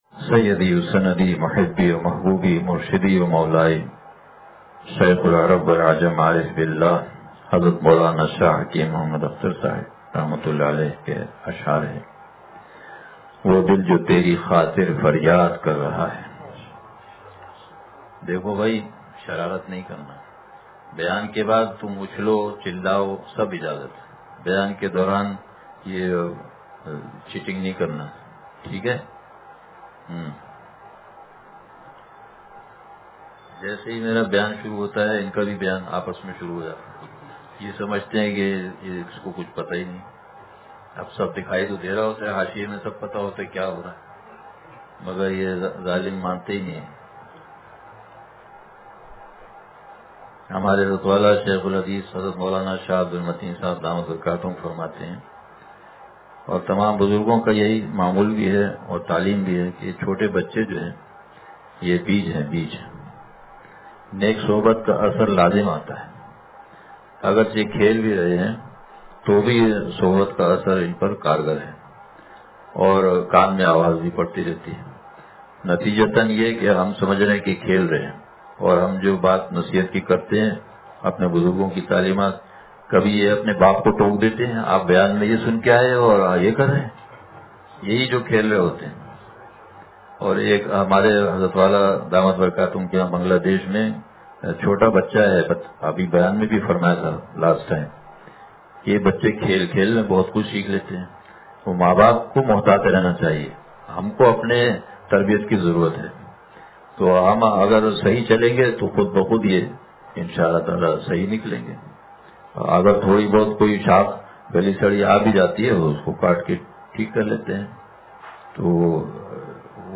وہ دل جو تیری خاطر فریاد کر رہا ہے – بچیوں کے حفظ و ختم قرآن کریم کے موقع پر بیان